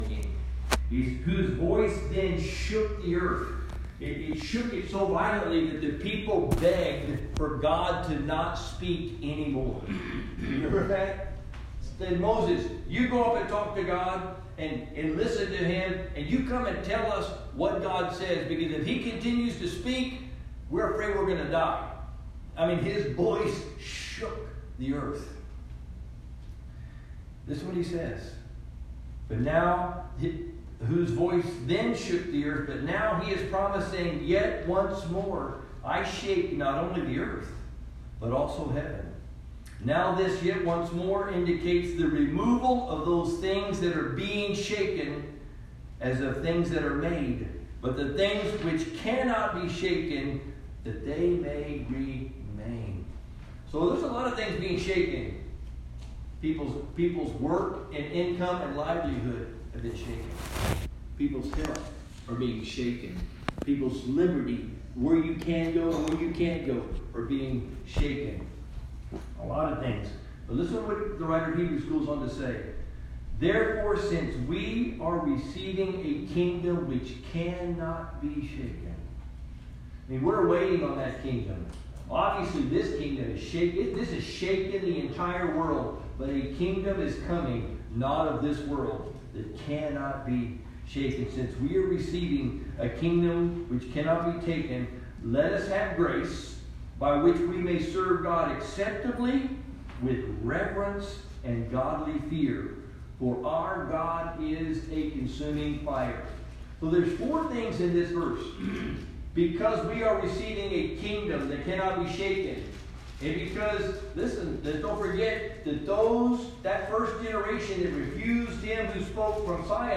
Sunday Message - University Park Baptist